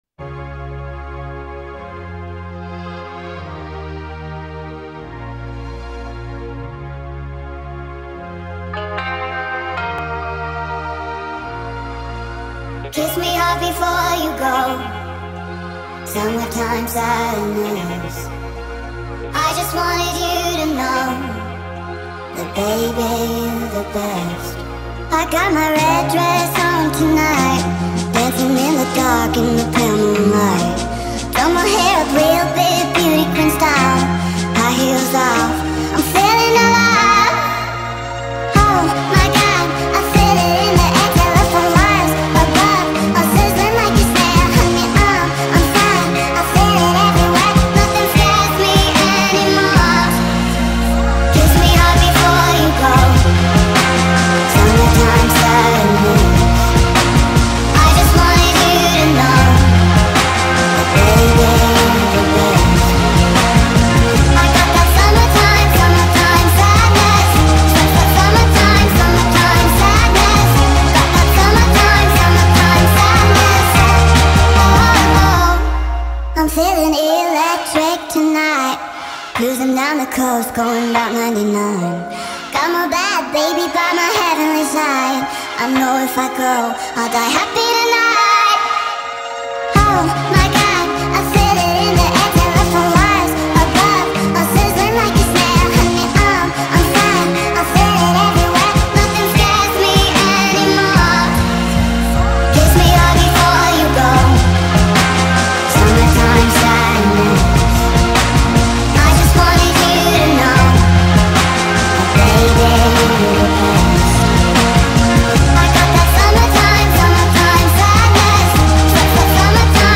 ریتمی سریع شده
غمگین
عاشقانه